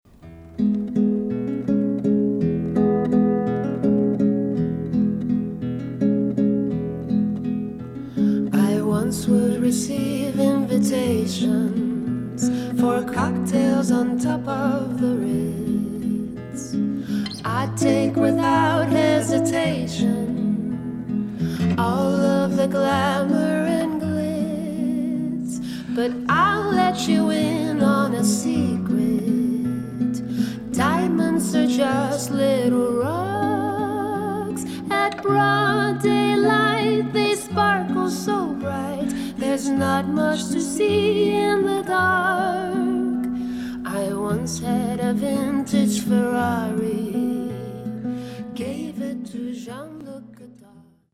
Charmant spielerisch kommt die Musik daher.